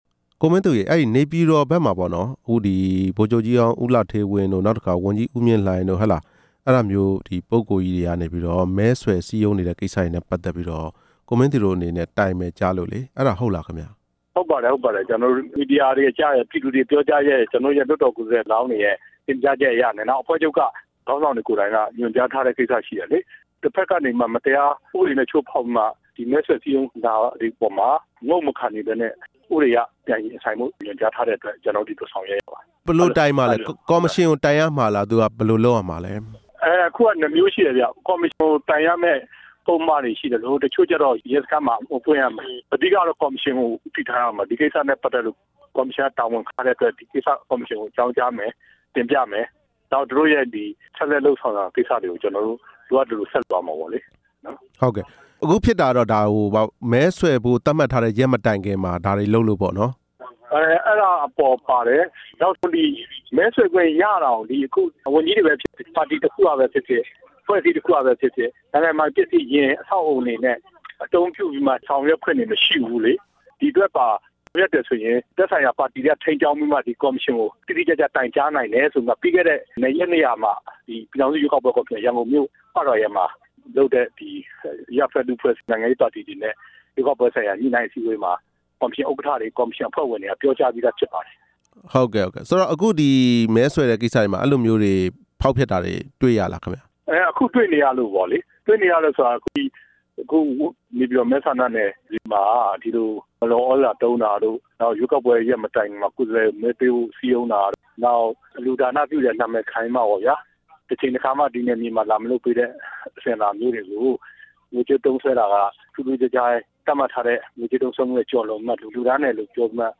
NLD လွှတ်တော်ကိုယ်စားလှယ် ဦးမင်းသူနဲ့ မေးမြန်းချက်